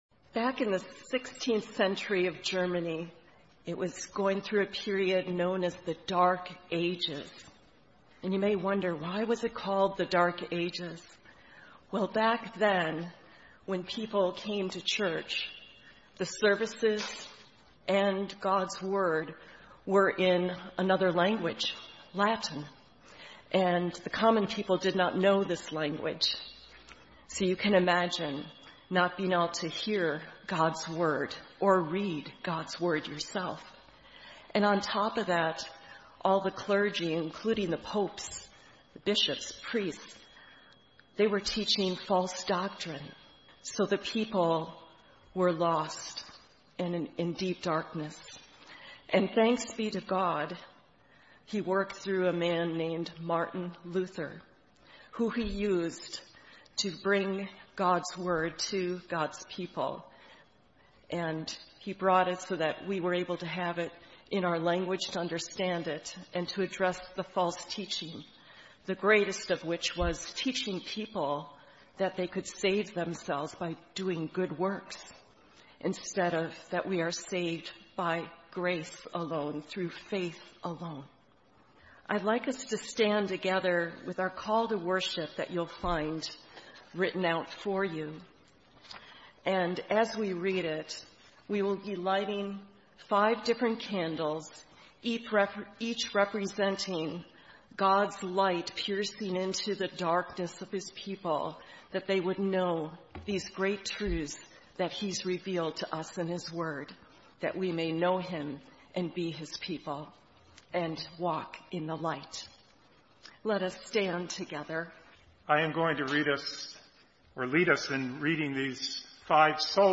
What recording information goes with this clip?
Thanks for joining us for our weekly worship! The services we post here were preached the previous week.